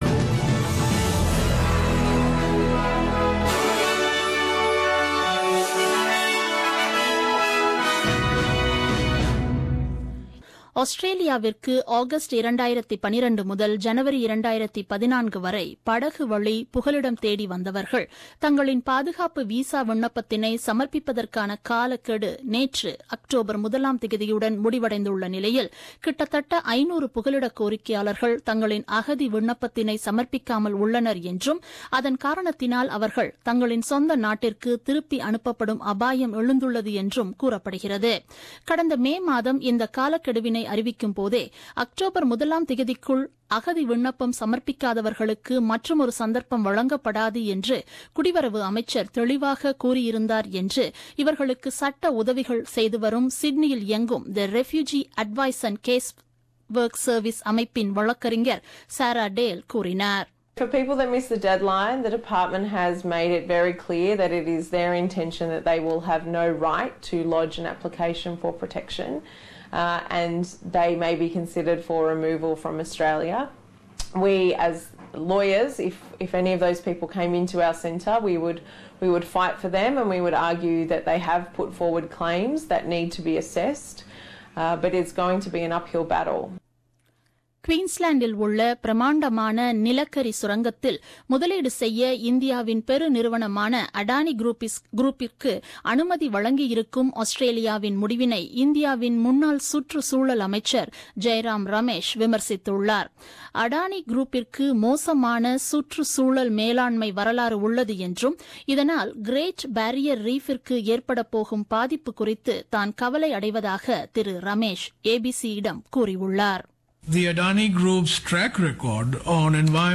The news bulletin broadcasted on 2nd October 2017 at 8pm.